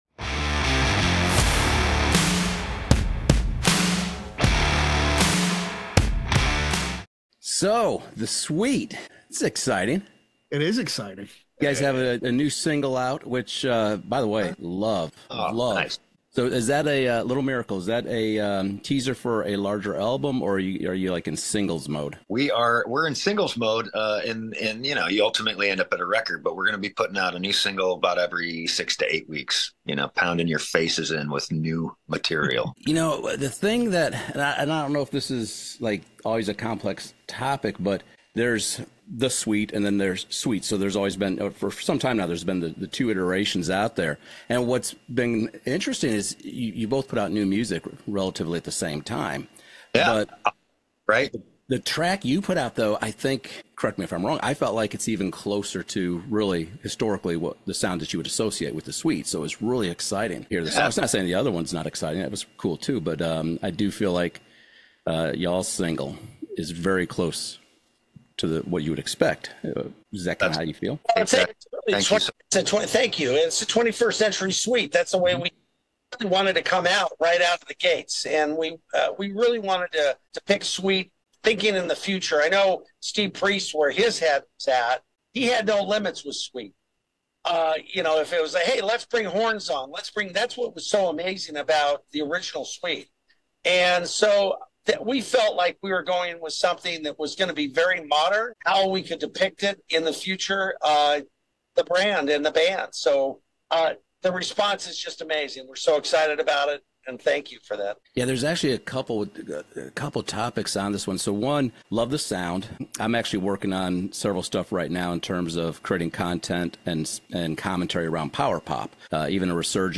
The Sweet isn’t reliving the past — they’re rewriting the future. In this Press Play Conversations interview